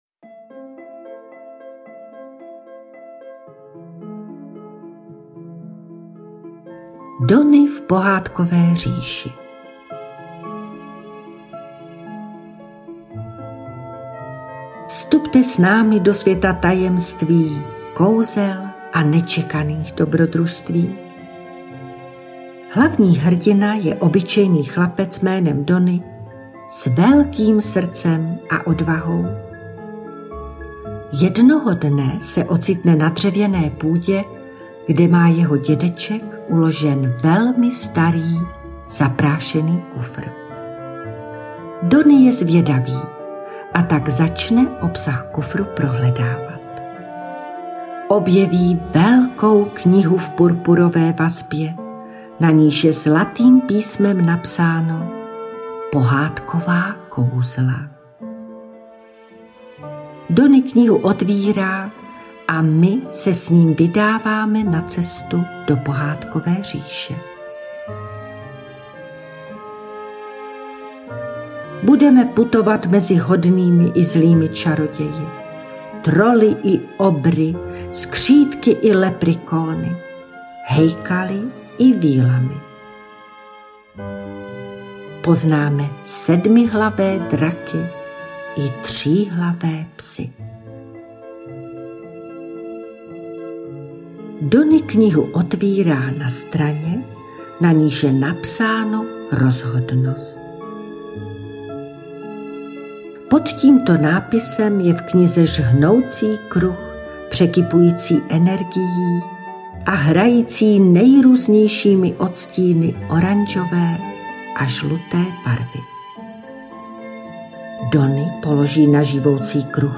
AudioKniha ke stažení, 5 x mp3, délka 45 min., velikost 41,0 MB, česky